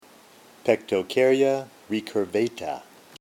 Pronunciation:
Pec-to-cár-y-a  re-cur-và-ta